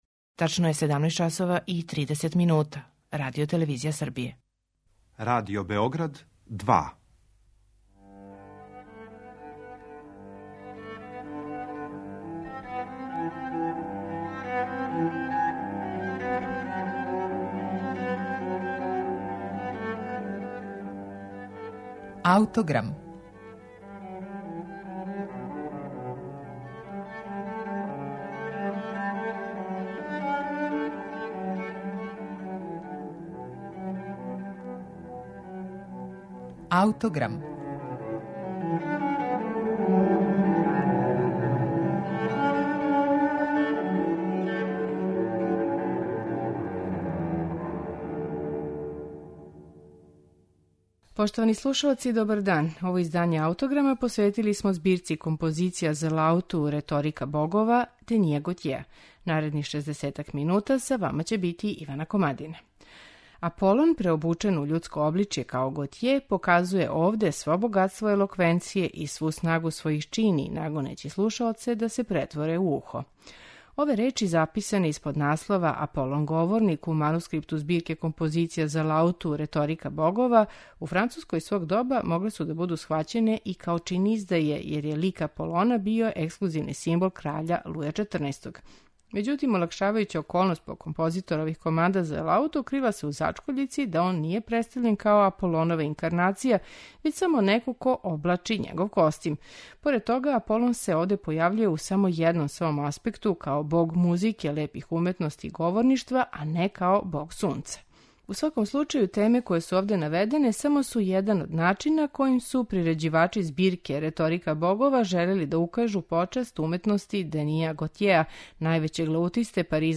Збирка композиција за лауту 'Реторика богова' Денија Готјеа